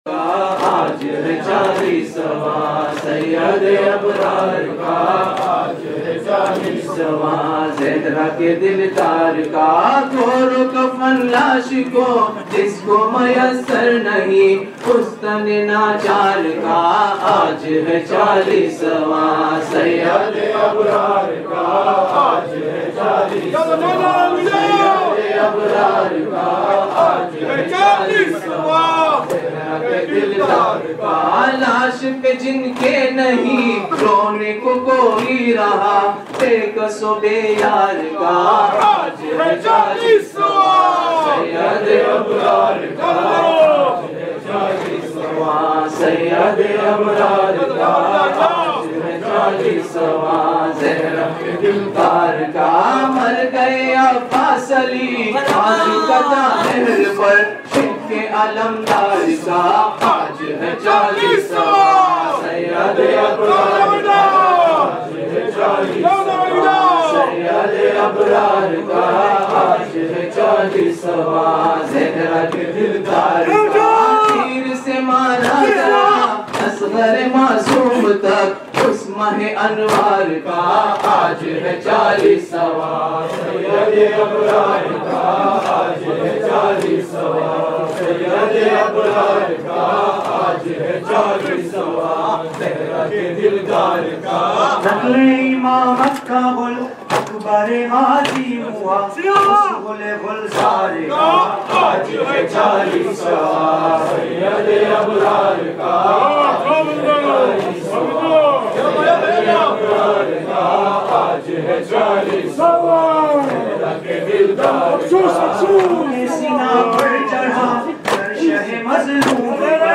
Chehlum / Arbaeen